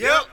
Yup Vox.wav